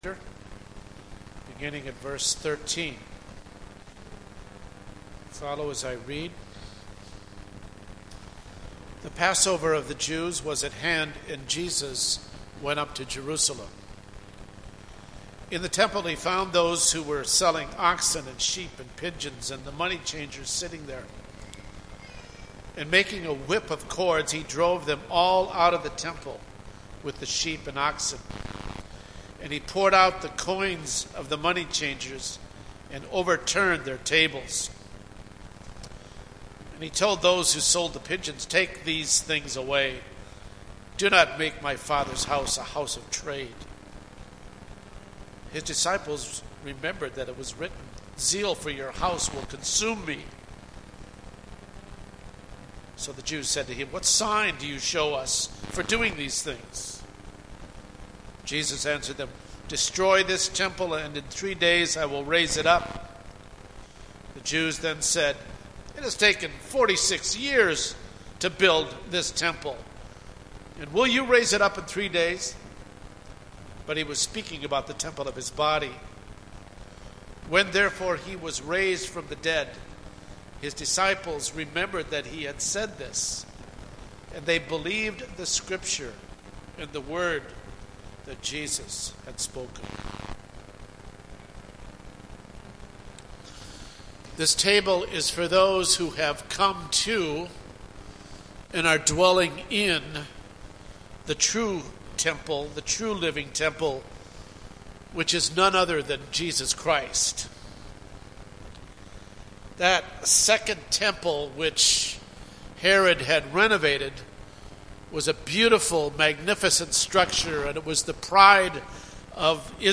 Communion Homily: Today You Will Be With Me In Paradise